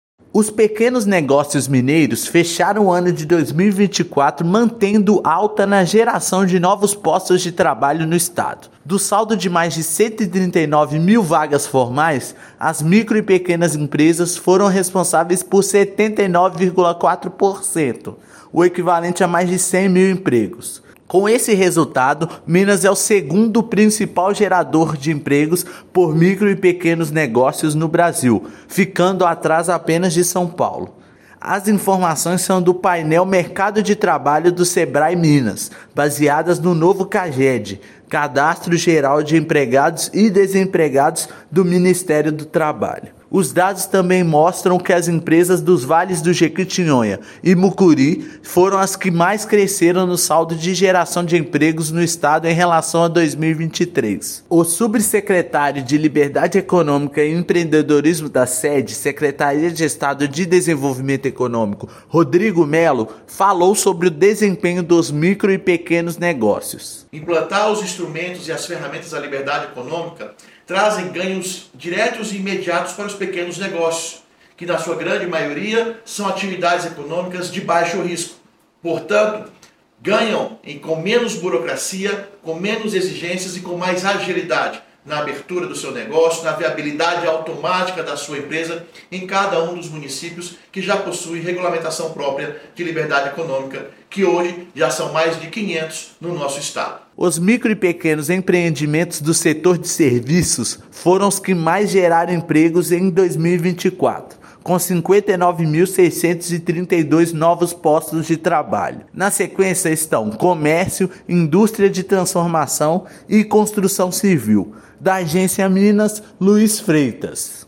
Estado foi líder nacional em 2024 na participação das MPEs no saldo de postos de trabalho. Ouça matéria de rádio.
Matéria_Rádio_-_Crescimento_MPEs.mp3